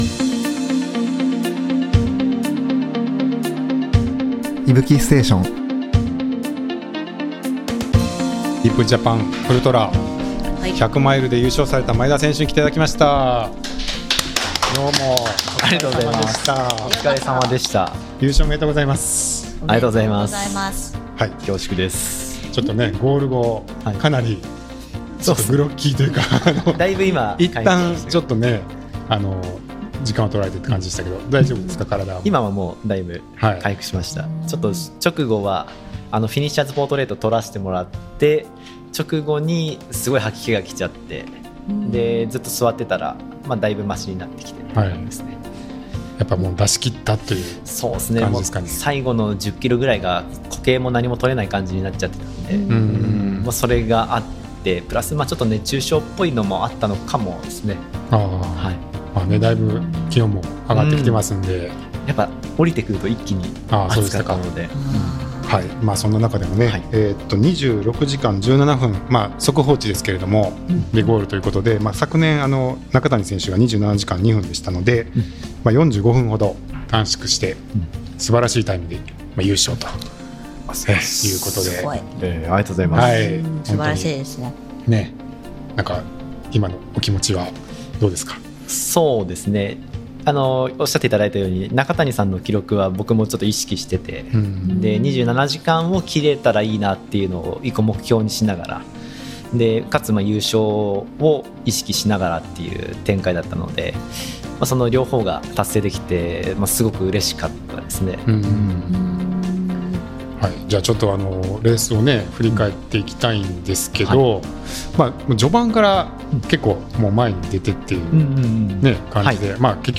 Deep Japan Ultra 100公式Liveから、選り抜きの内容をポッドキャストでお届けします！